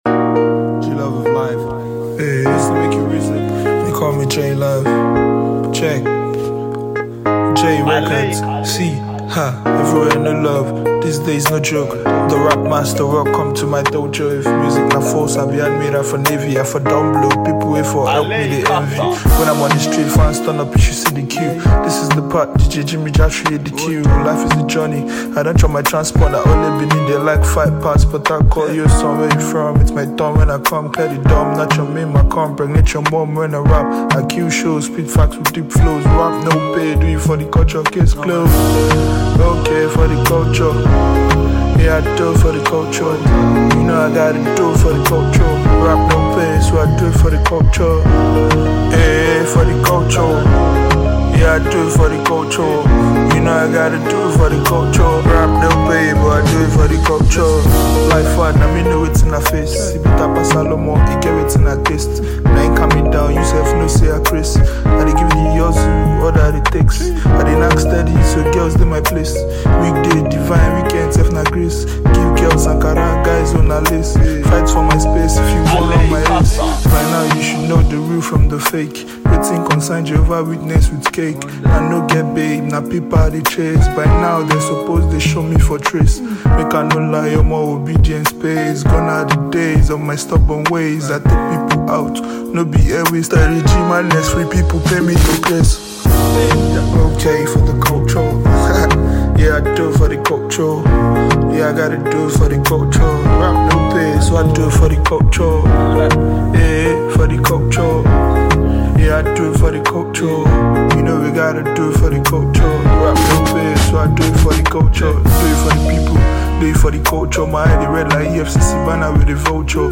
it’s a celebration of the essence and spirit of hip-hop